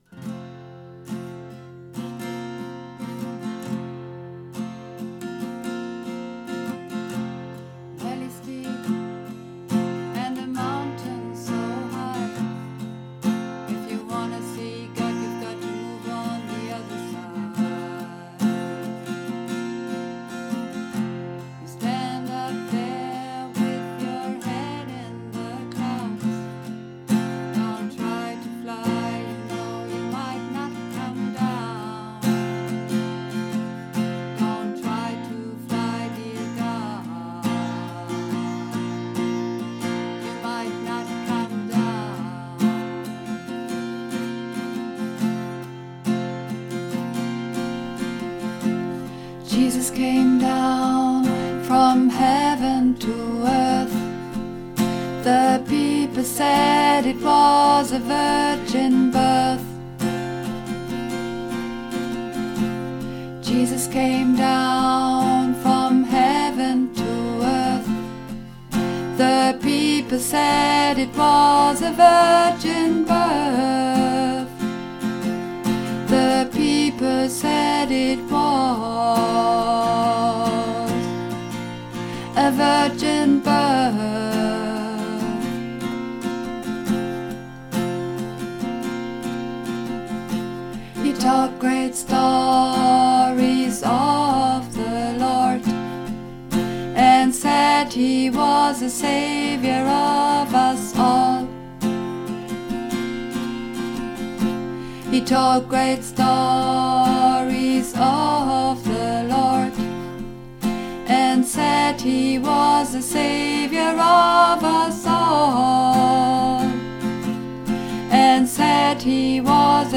Übungsaufnahmen - Hymn
Hymn (Alt)
Hymn__1_Alt.mp3